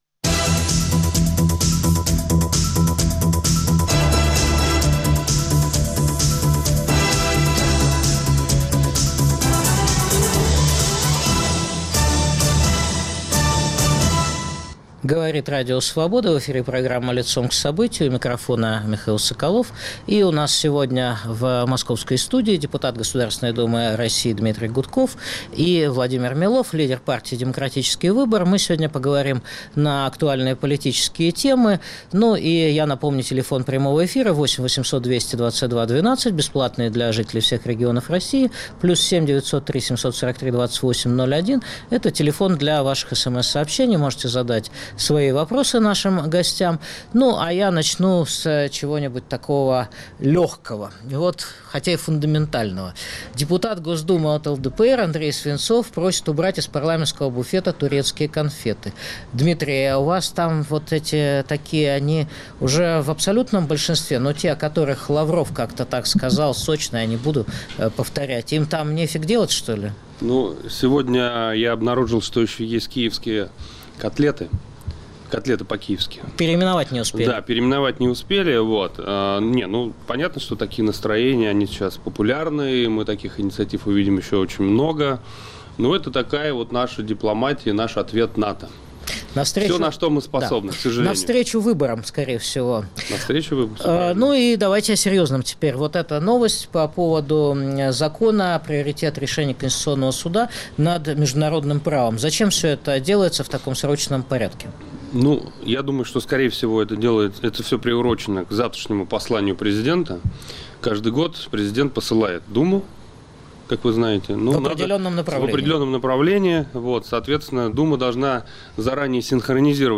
Что делать, когда бандиты во власти, а война на пороге? Обсуждают независимый депутат Госдумы РФ Дмитрий Гудков и лидер партии "Демократический выбор" Владимир Милов.